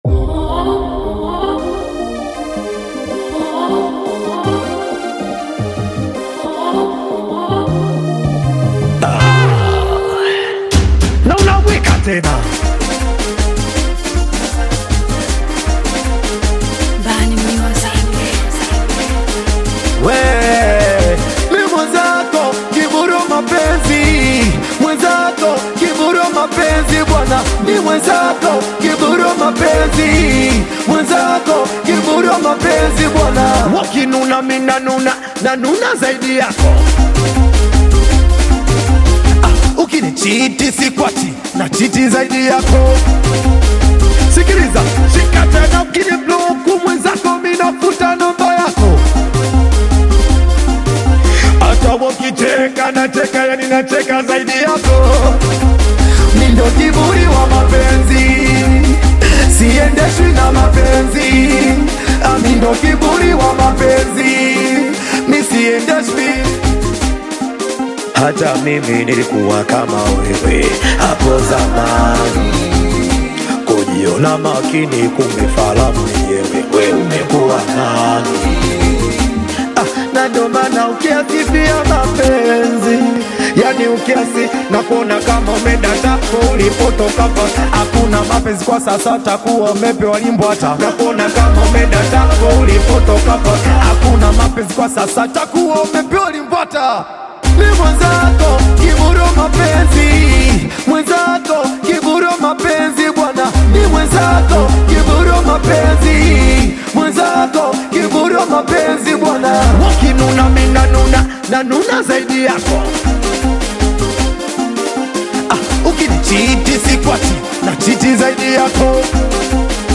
a lively Singeli/Urban track
signature upbeat flow